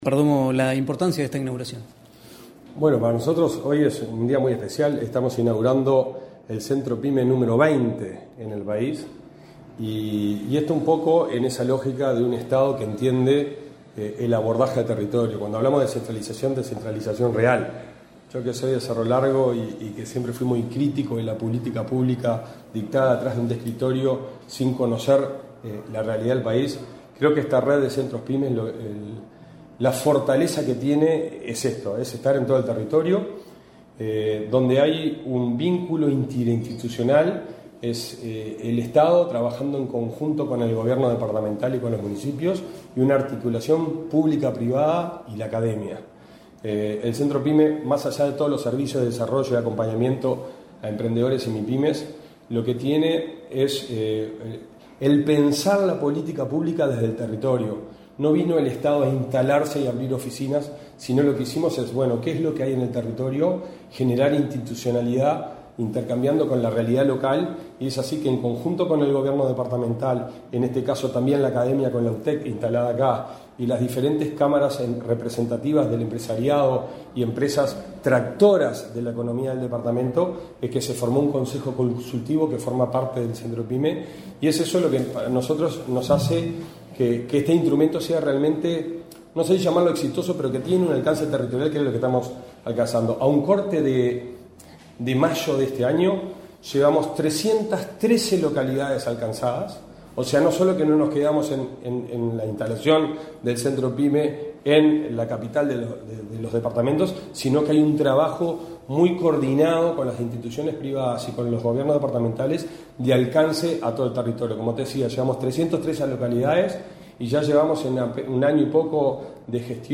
Entrevista al integrante del directorio de la ANDE, Federico Perdomo